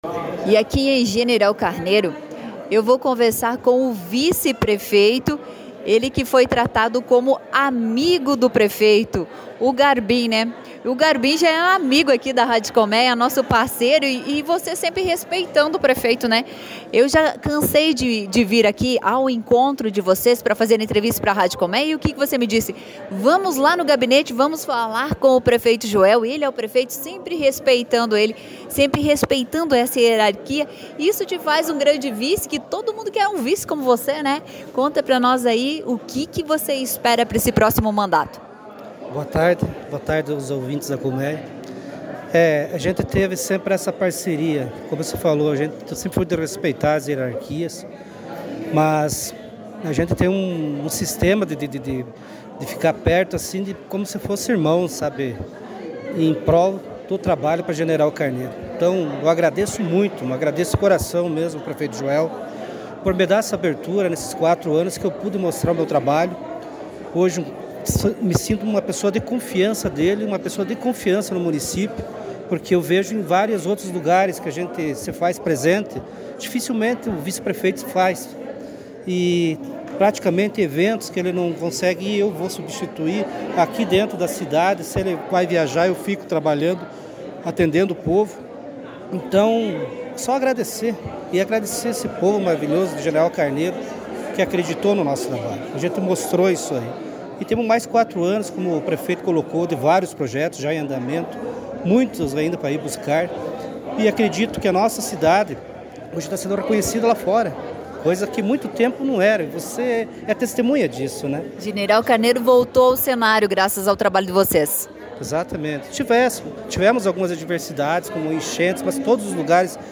E também com o Vice Célio Garbin: